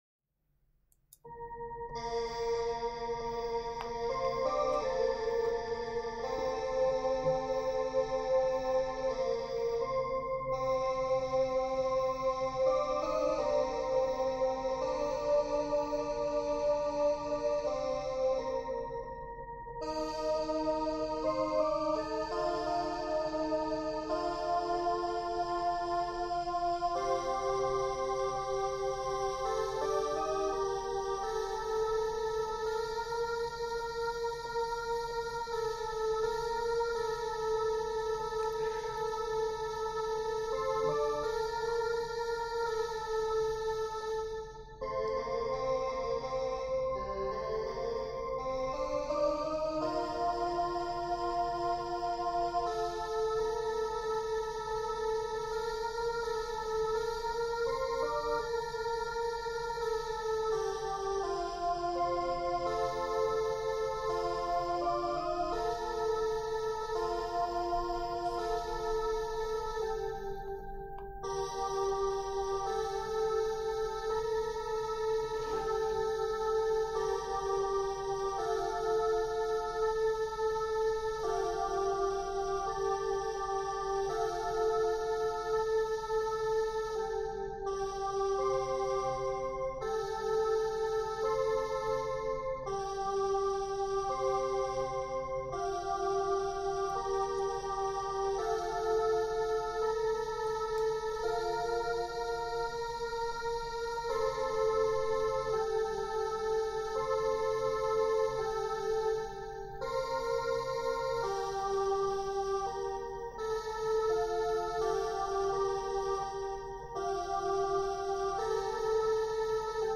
meter = "Largo"
\set Staff.midiInstrument = "pad 4 (choir)"
\set Staff.midiInstrument = "drawbar organ"